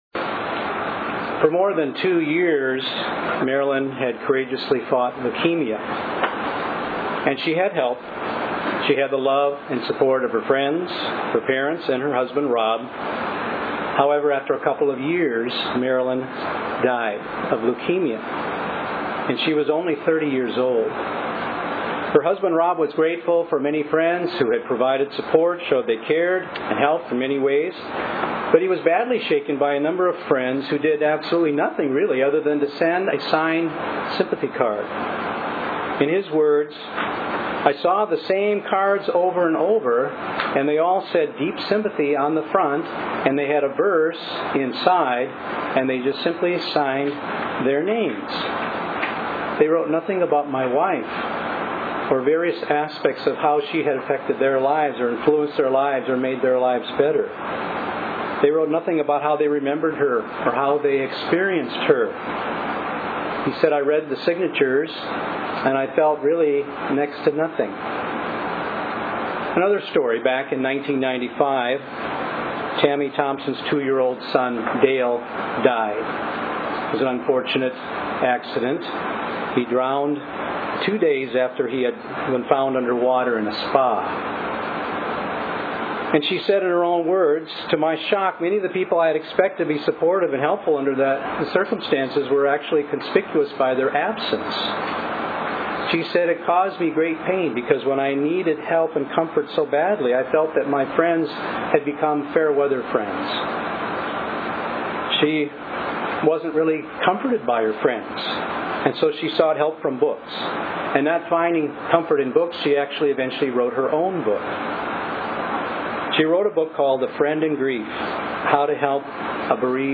This sermon will address consoling one another in the midst of grief